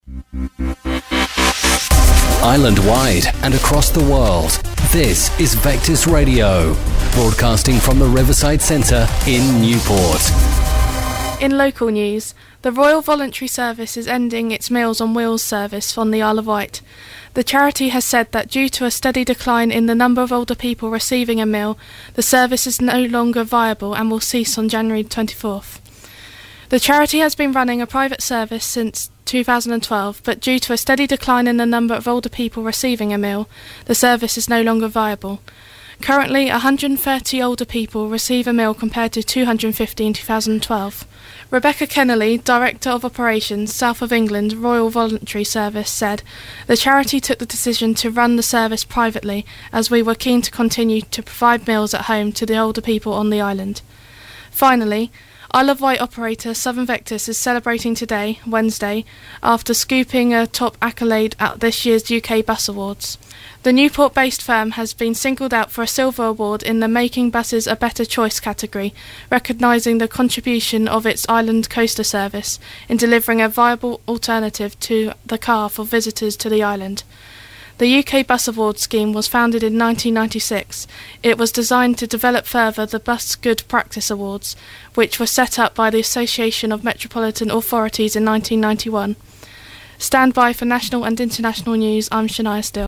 News Read Out.